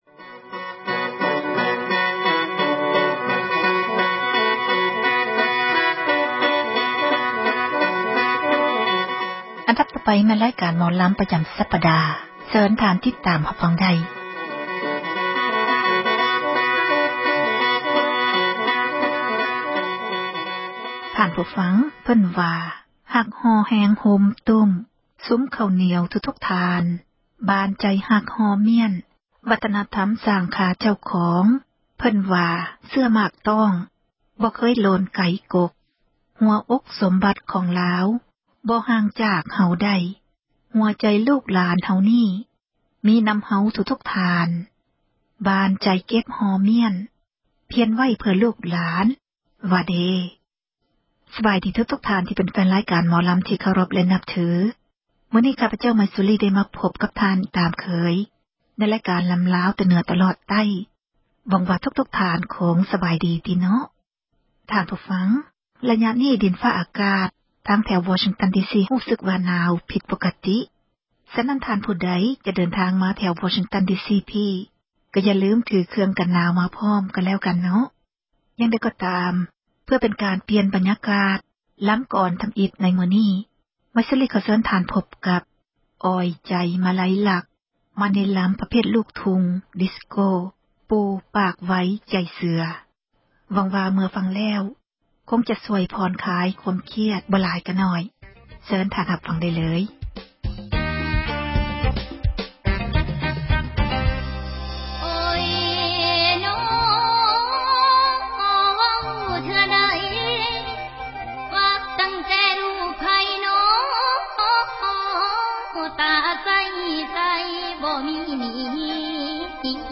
ຣາຍການ ໝໍລໍາ ແຕ່ເໜືອ ຕລອດໃຕ້ ຈັດມາສເນີທ່ານ